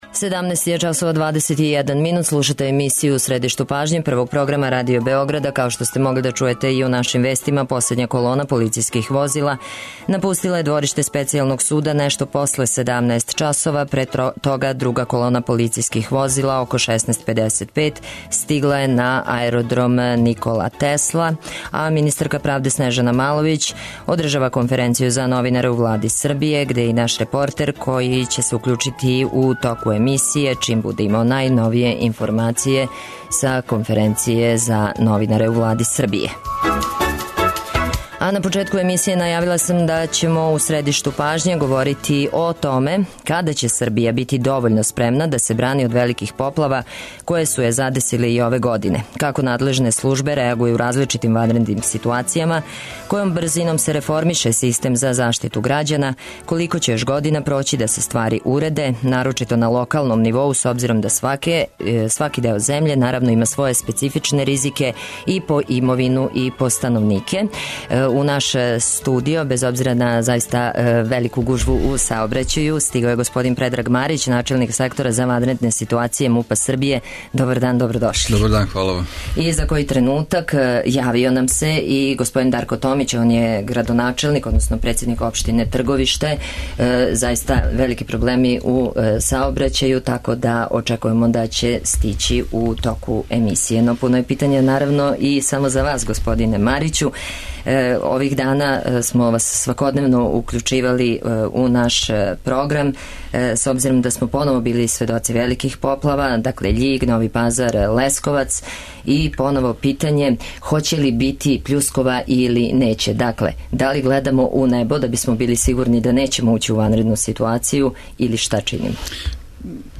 Гости: Предраг Марић, начелник Сектора за ванредне ситуације МУП-а Србије и Дарко Томић, председник општине Трговиште.